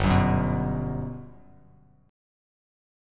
GM7 Chord
Listen to GM7 strummed